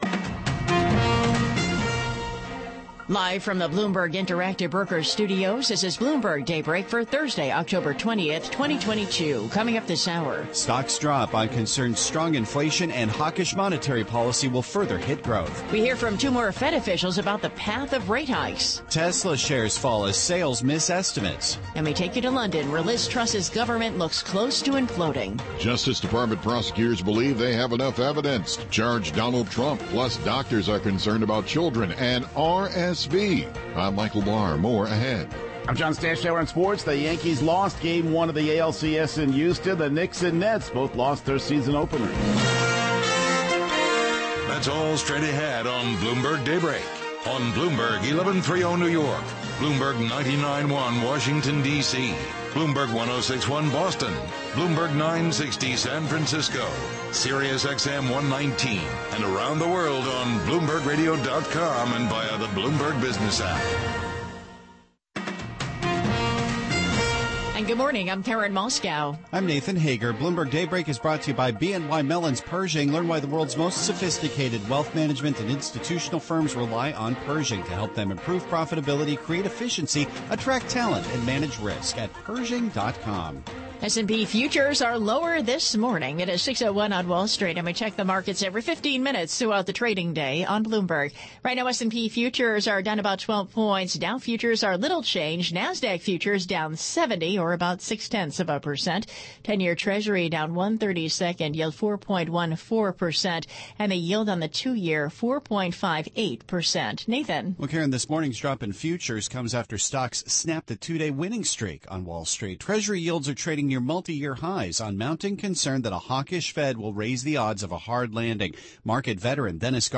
GUESTS: Jim Bullard President/CEO Federal Reserve Bank St Louis